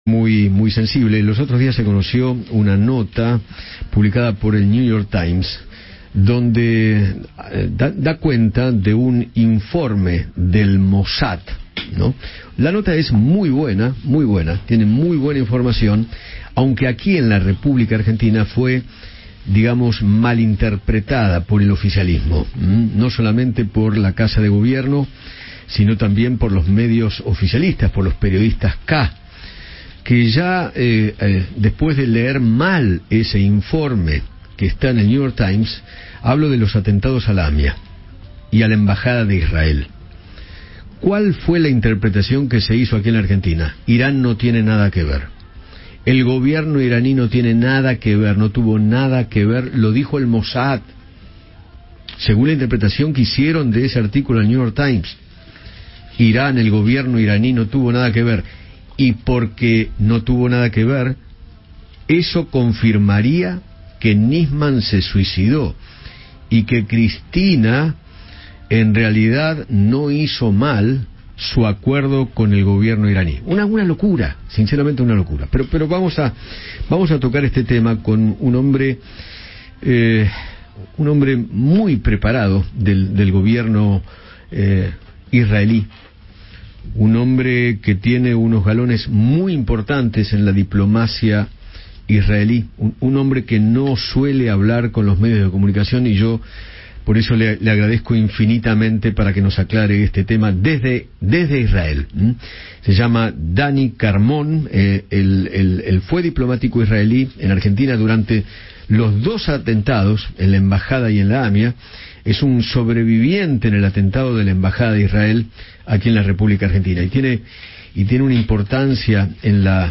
Eduardo Feinmann conversó con Daniel Carmon, ex cónsul de la embajada de Israel en Argentina, sobre el informe del Mossad acerca de los ataques terroristas sufridos por la comunidad judia en nuestro país.